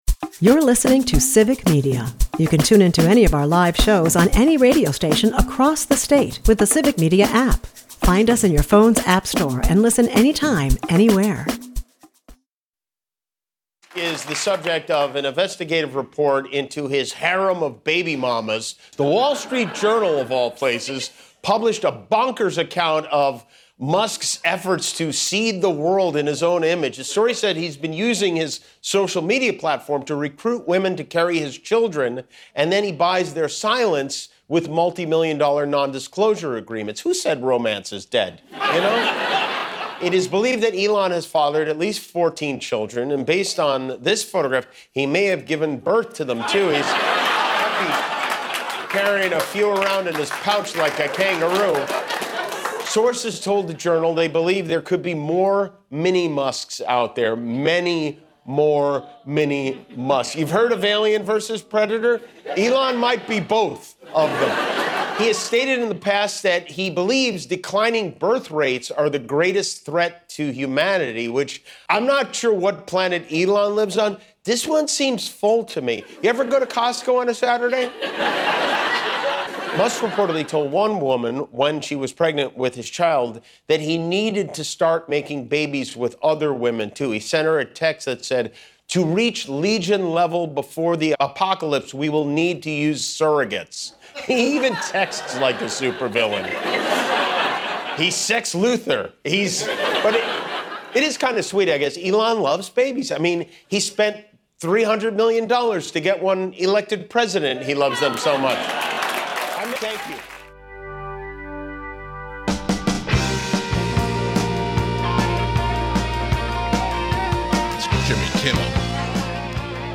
Later, we welcome on former Madison Mayor Paul Soglin, talking this time around about how universities like Harvard are taking a stand against government overreach, in favor of academic freedom.